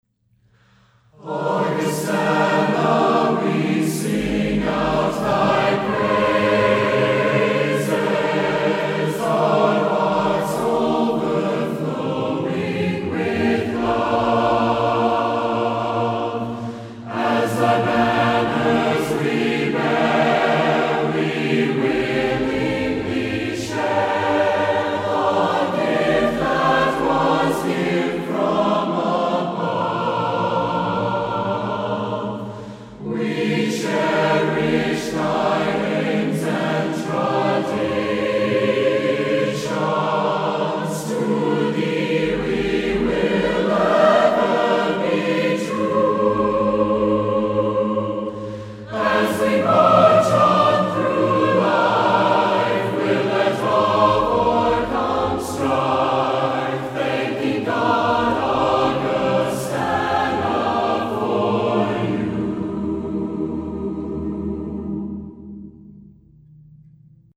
Performed by The Augustana Choir